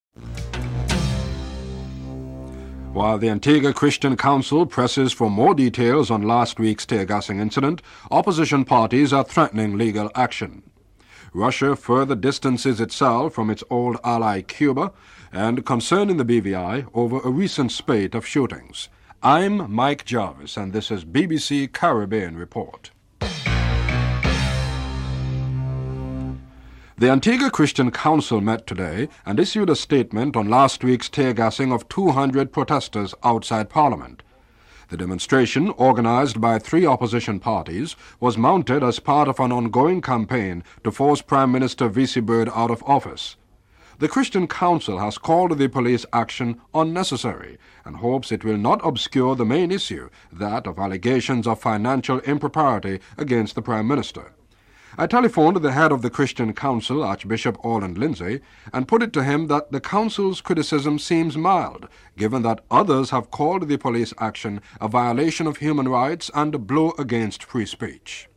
Governor Peter Penfold talks about the seriousness of the problem (09:11-12:53)
Captain Richie Richardson comments on his emotional preparedness (12:54-13:51)